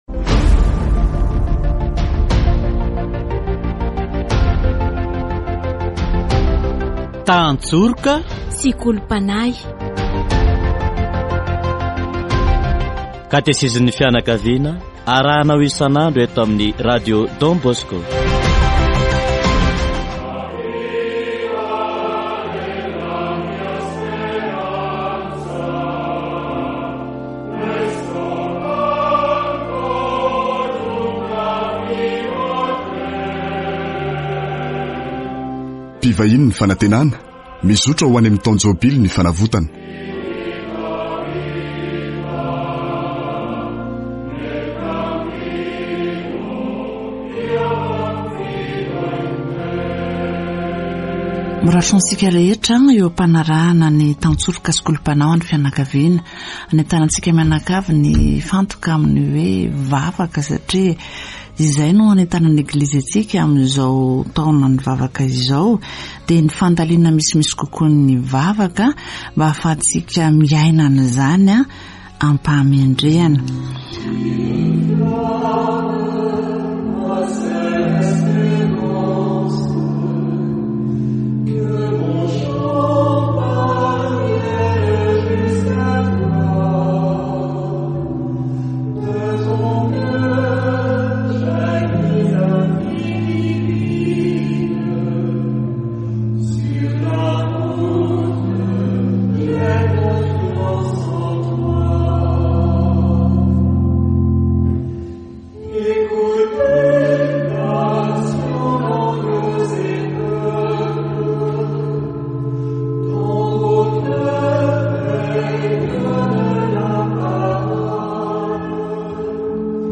Catechesis on Mary